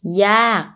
yaag